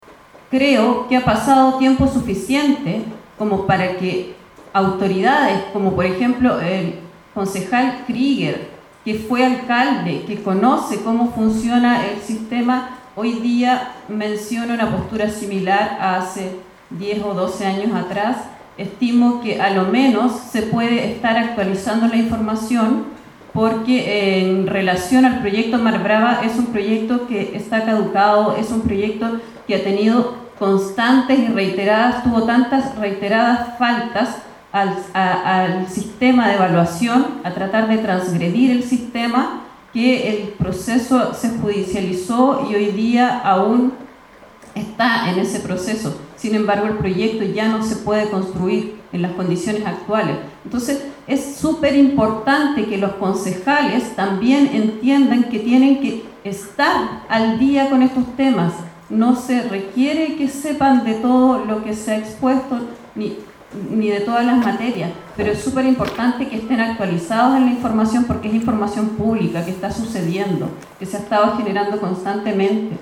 Más de un centenar de personas participó de esta audiencia pública realizada en Ancud en torno a las amenazas al territorio de Chiloé por los proyectos de energía eólica y carreteras eléctricas.